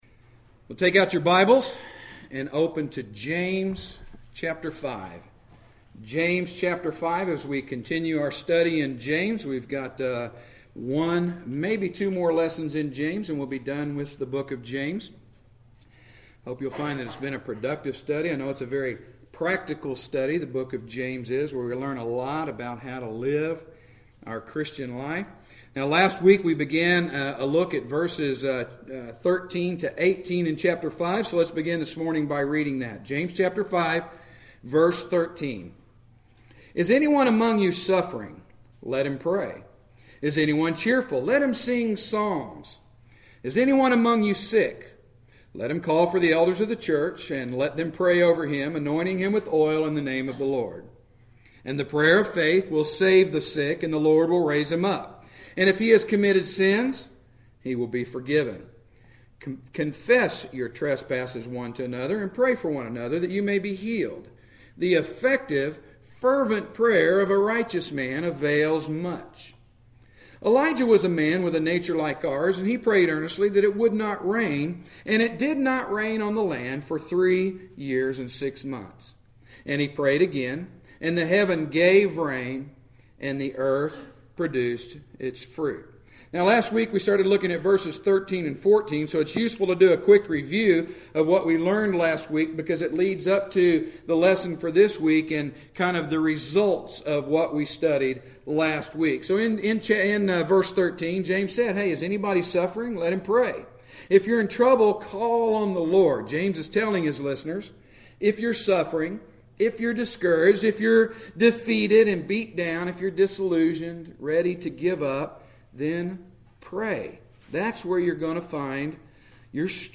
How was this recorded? Preached at Straightway Bible Church on Sept 15, 2013.